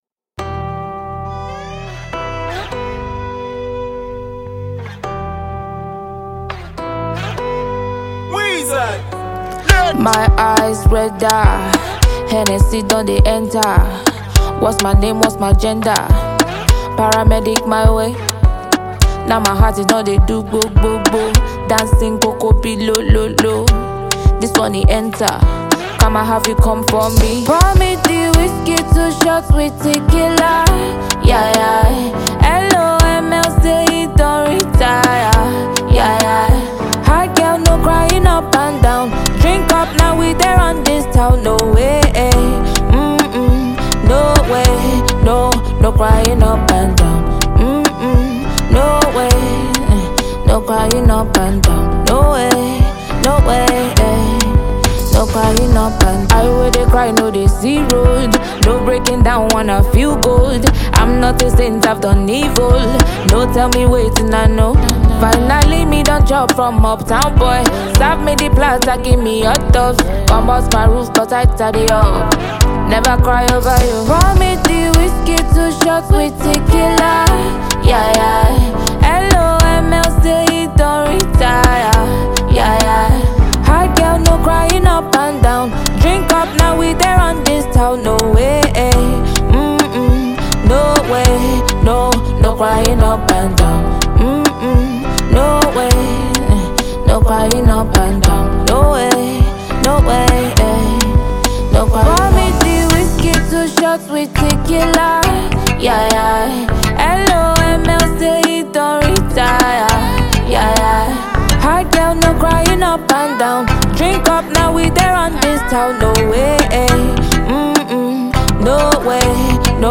Nigerian / African Music
Genre: Afrobeats